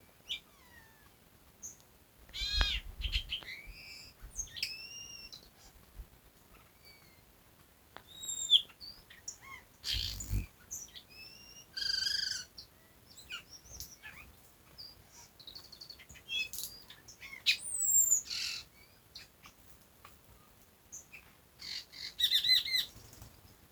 Boyerito (Icterus pyrrhopterus)
Nombre en inglés: Variable Oriole
Localidad o área protegida: Concepción del Yaguareté Corá
Localización detallada: Sendero Carambolita
Condición: Silvestre
Certeza: Observada, Vocalización Grabada